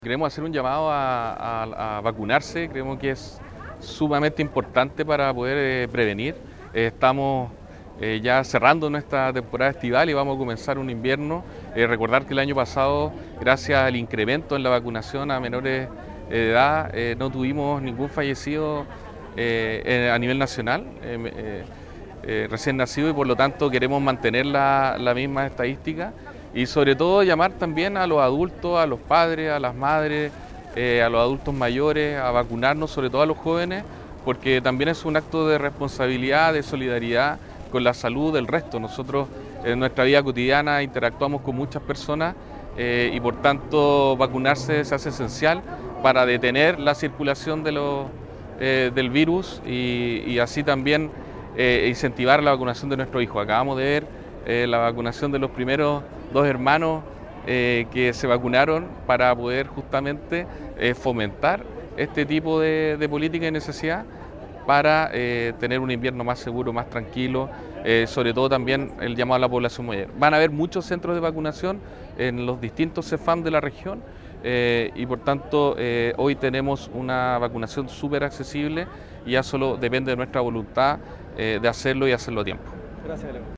Tras participar de la actividad de lanzamiento regional, el Delegado Presidencial Regional, Galo Luna, explicó la importancia de sumarse a la estrategia preventiva de este invierno
CUNA-DELEGADO-PRESIDENCIAL_VACUNACION.mp3